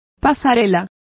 Complete with pronunciation of the translation of gangways.